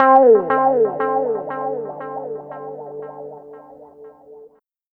Track 13 - Delayed Guitar Outro.wav